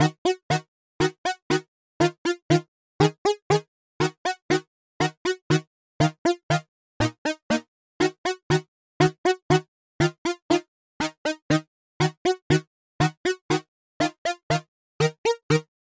镗孔黄铜
描述：罗兰管弦乐
标签： 120 bpm Hip Hop Loops Brass Loops 1.35 MB wav Key : Unknown
声道立体声